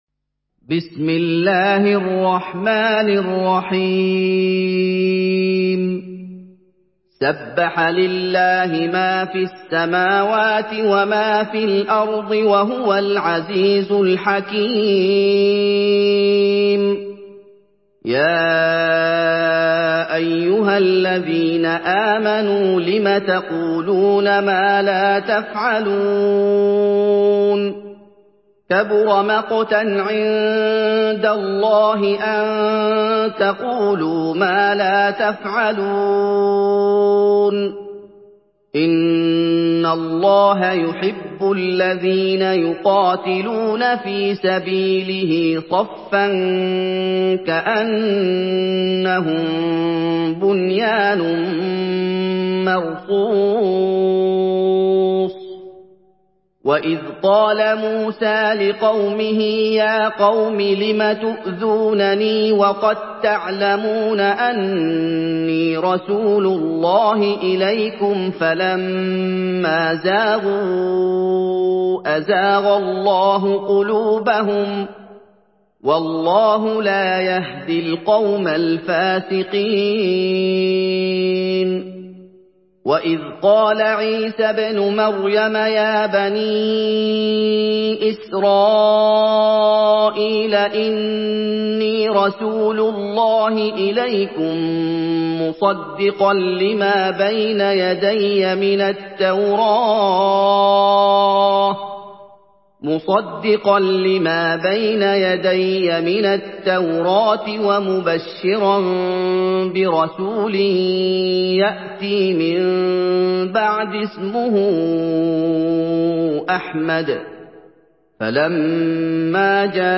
Surah আস-সাফ MP3 in the Voice of Muhammad Ayoub in Hafs Narration
Murattal Hafs An Asim